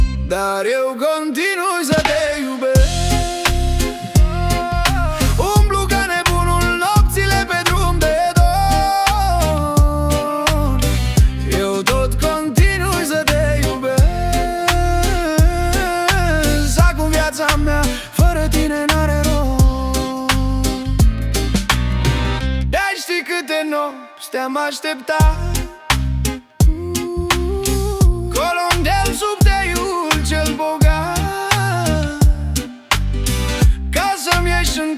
Folk Dance
Жанр: Танцевальные / Фолк